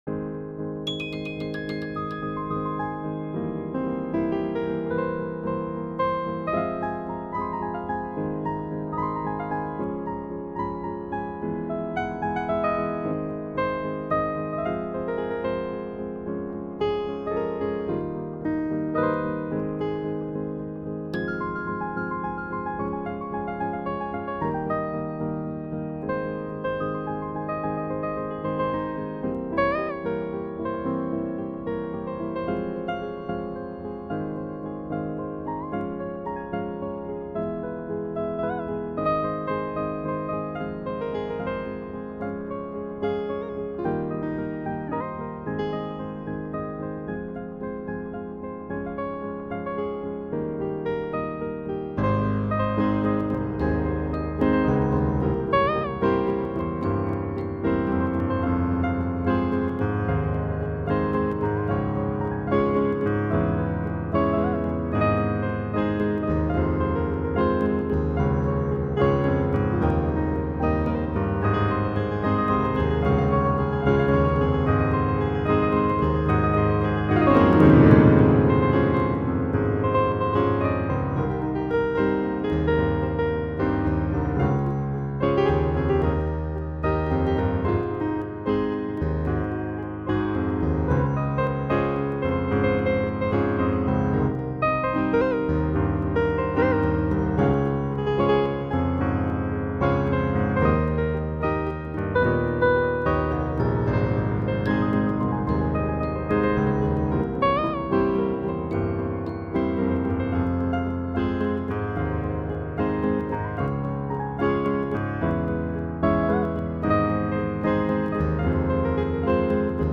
🎧 Audio demonstration Click to listen